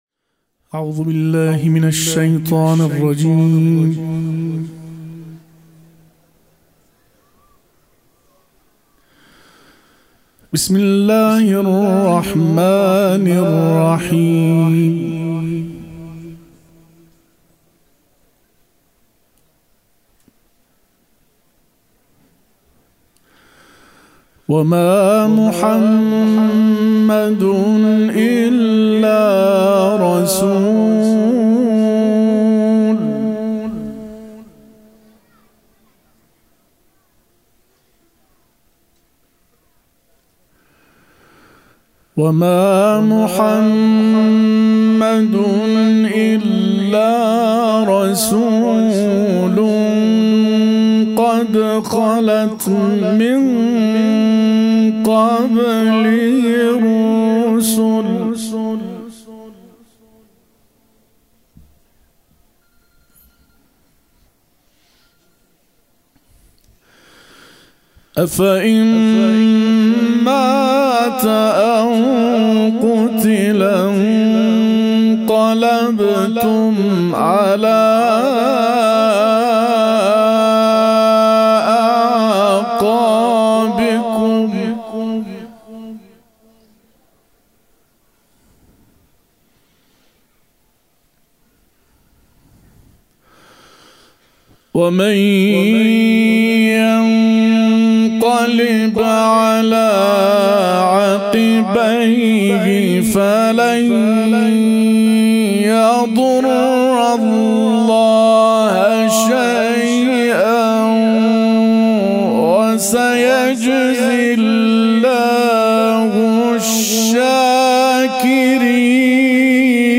به گزارش خبرنگار فرهنگی خبرگزاری تسنیم، مراسم ویژه گرامیداشت جانباختگان حادثه هوایی، شب گذشته در مهدیه امام‌حسن مجتبی (ع) با حضور مداحان اهل‌بیت و عموم مردم برگزار شد.